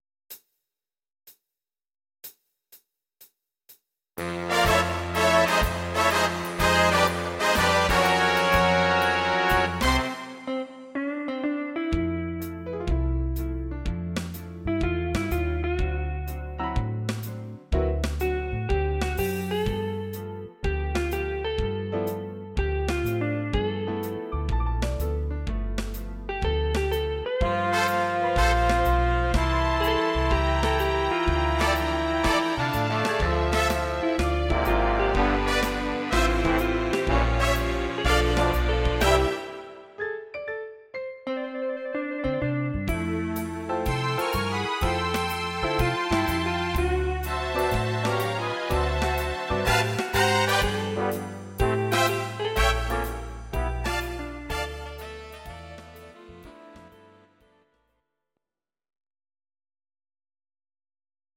Audio Recordings based on Midi-files
Pop, Musical/Film/TV, Jazz/Big Band, 1990s